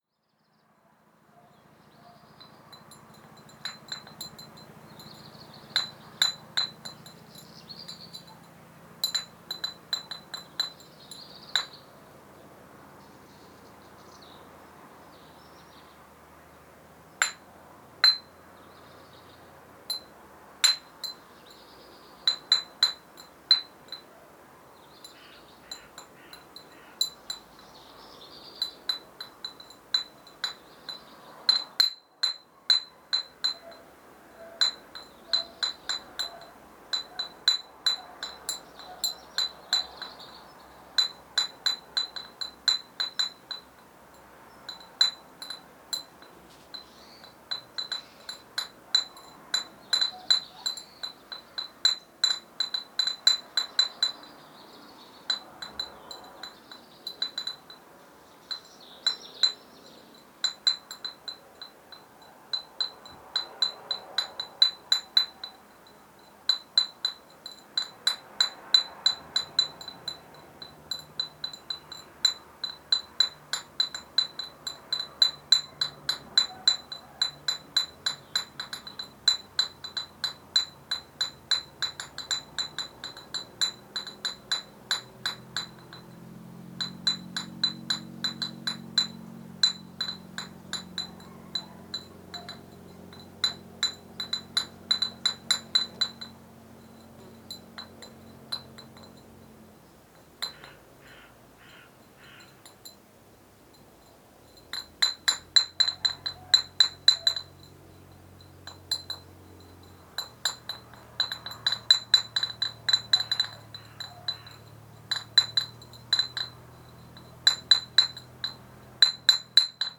Manipulação de garrafas junto a casa agricola abandonada em Baiúca de Oliveira.